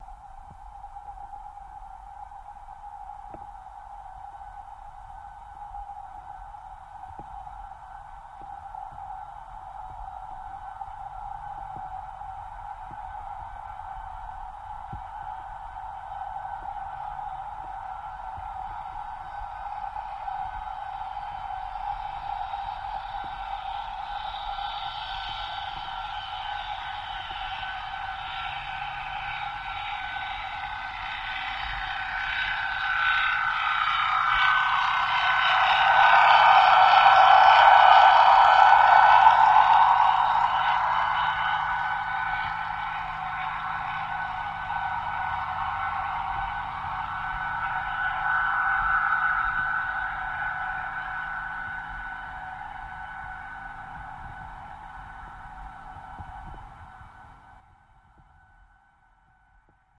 Torpedos
Torpedo Jet By